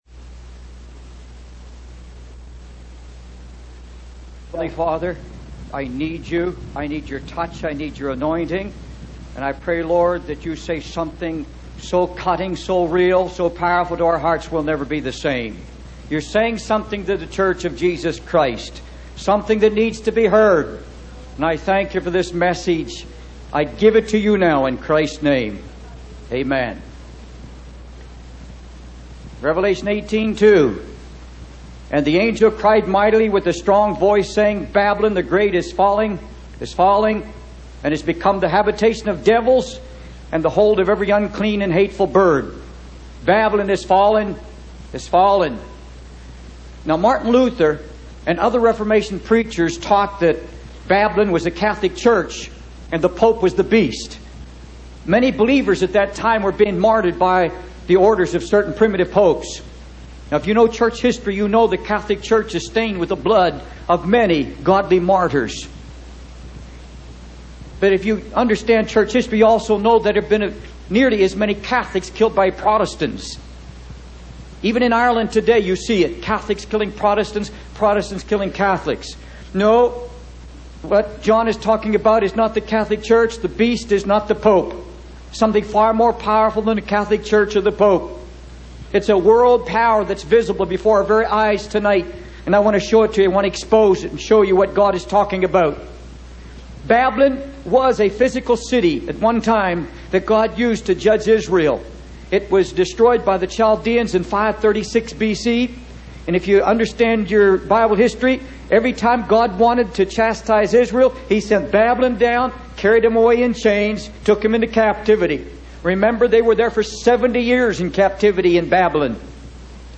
In this sermon, the preacher discusses the emergence of a new wave of Holy Ghost music in the church. He emphasizes the importance of worship and praise, particularly through the revival of the great hymns of the church. The preacher expresses concern about the worldliness infiltrating the church, with ministers borrowing music styles and choreography from the secular world.